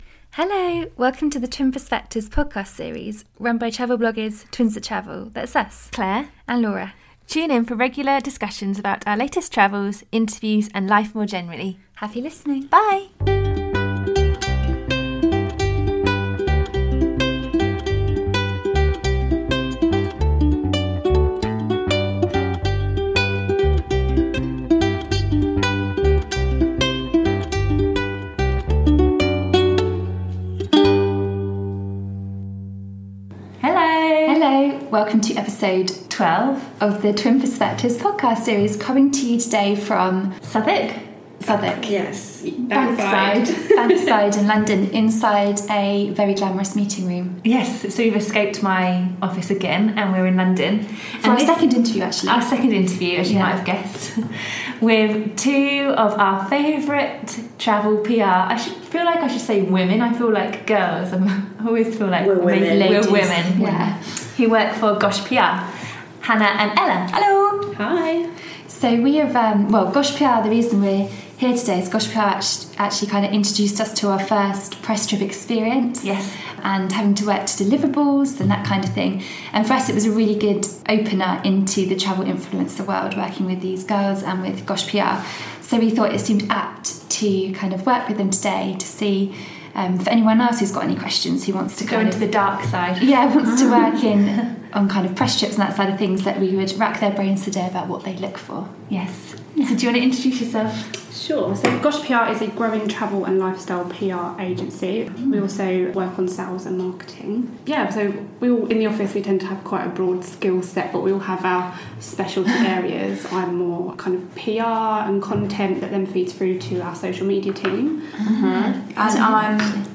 An Interview with Gosh PR